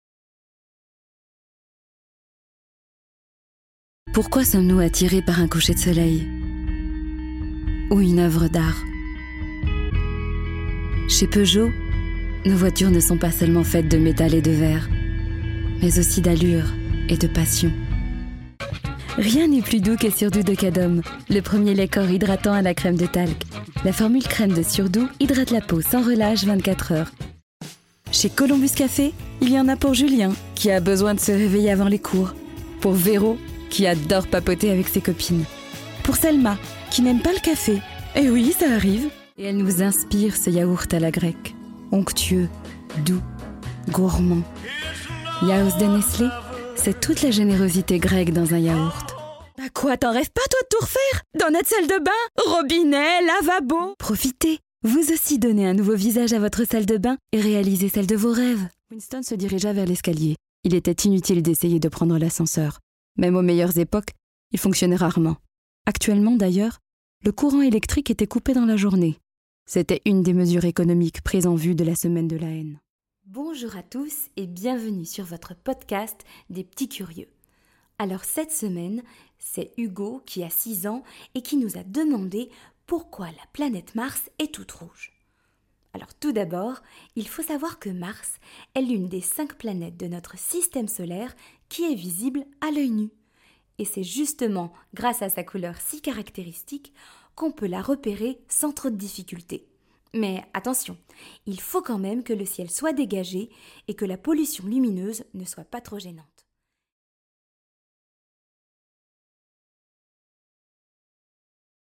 démo voix off
Comédienne
- Contralto